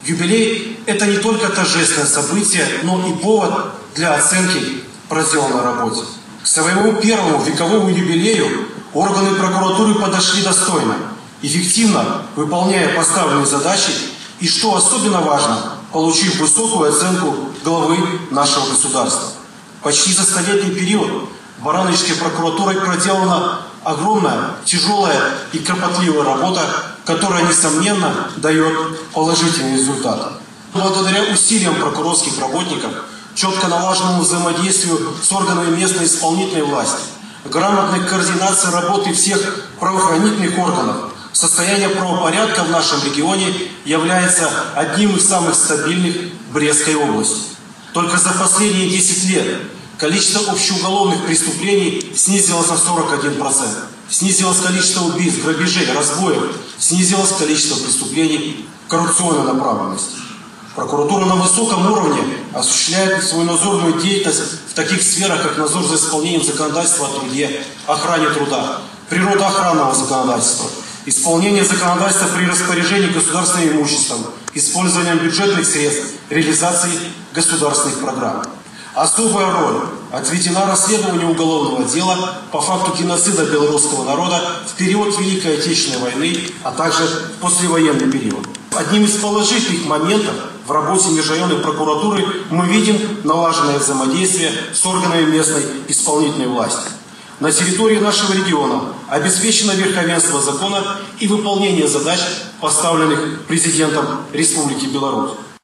Торжество состоялось в городском Доме культуры.